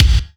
KICK142.wav